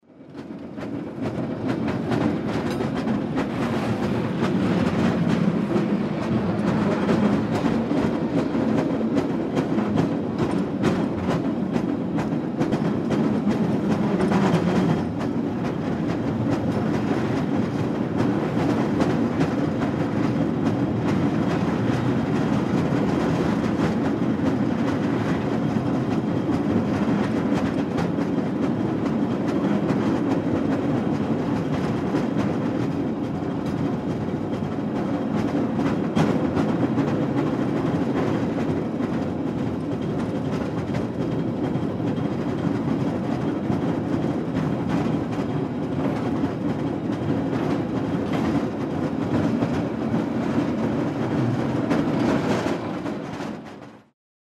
Поездка в трамвае